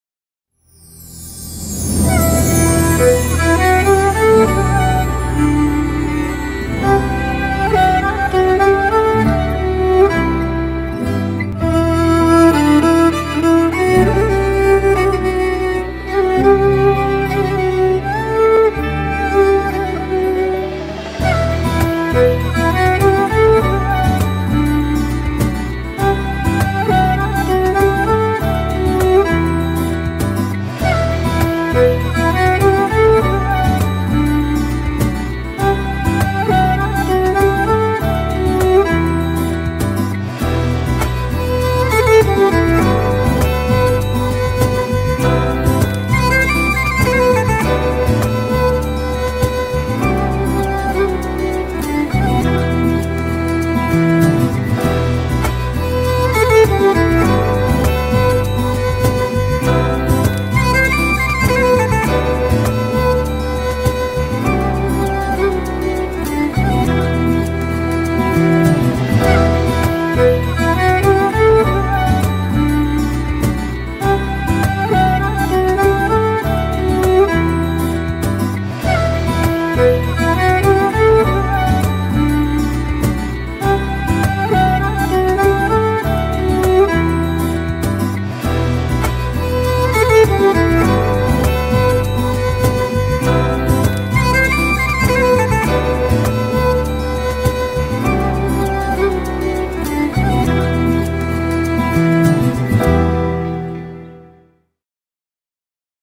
duygusal huzurlu rahatlatıcı fon müziği.